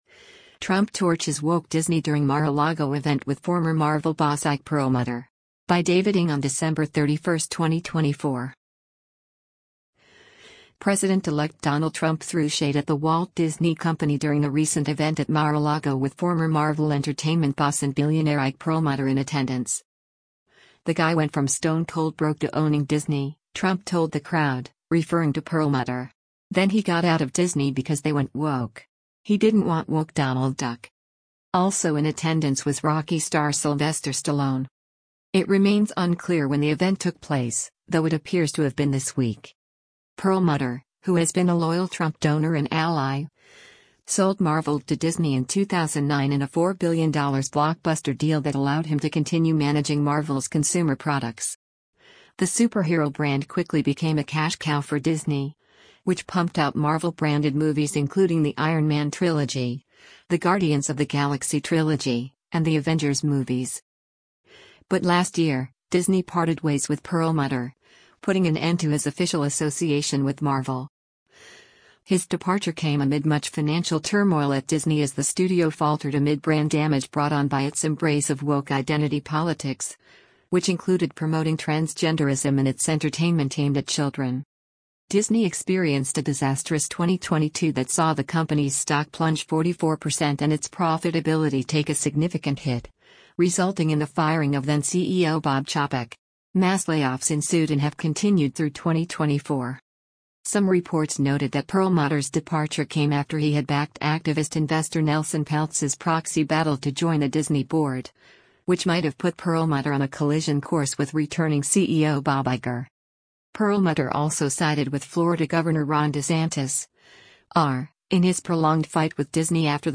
President-elect Donald Trump threw shade at the Walt Disney Company during a recent event at Mar-a-Lago with former Marvel Entertainment boss and billionaire Ike Perlmutter in attendance.